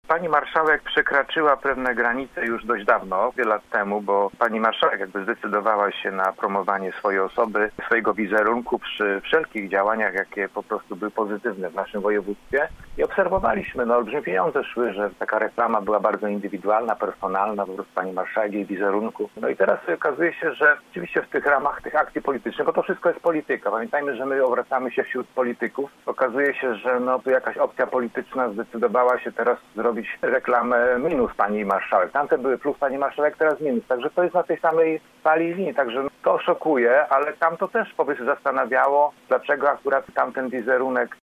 Radny był gościem Rozmowy po 9.